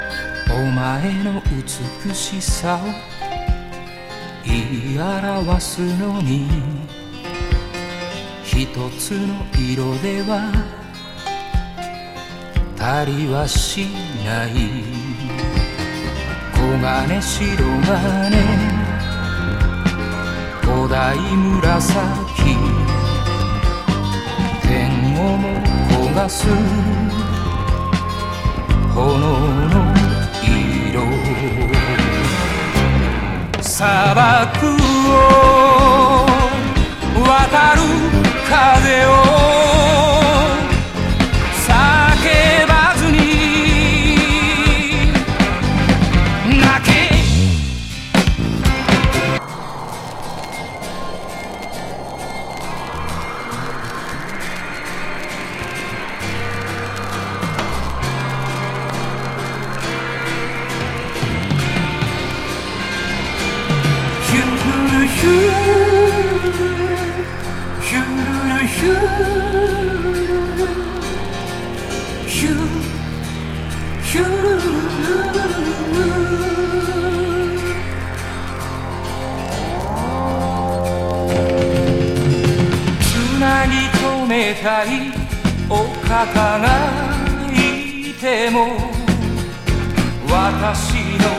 シンセ入りフォーキー・ロック